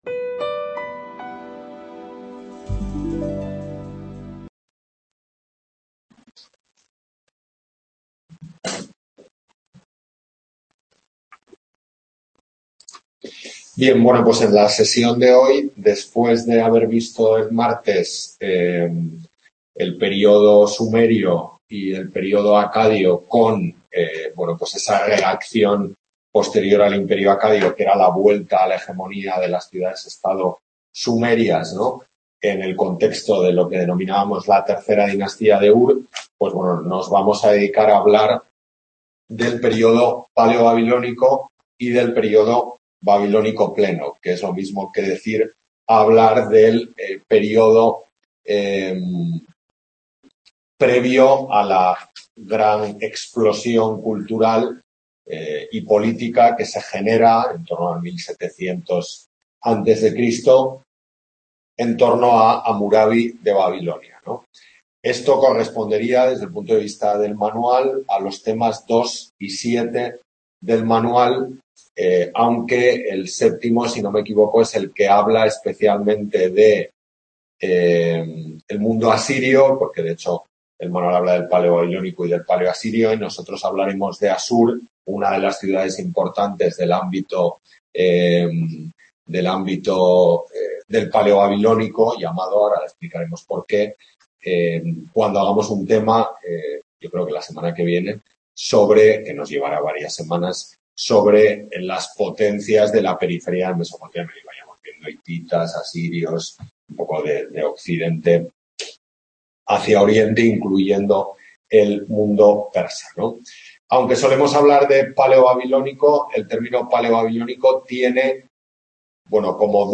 Tutoría de Historia Antigua I en la UNED de Tudela